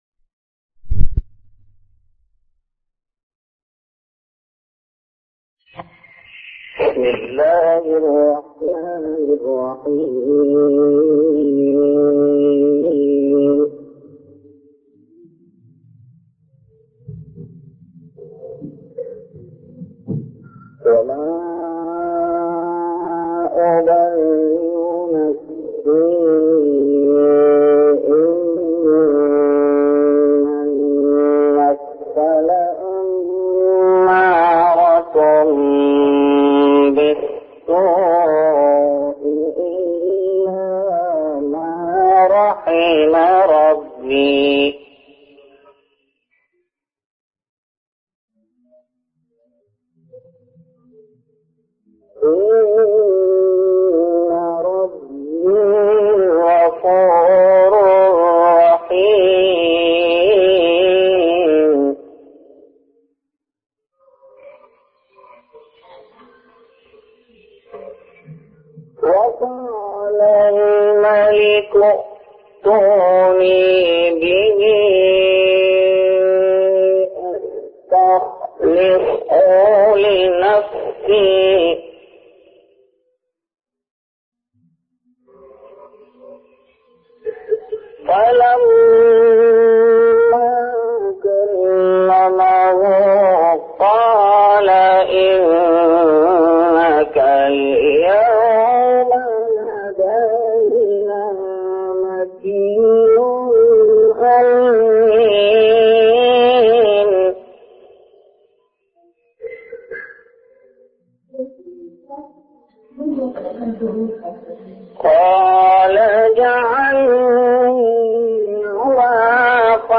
Islahi bayan mp3